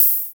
MB Hi Hat (16).wav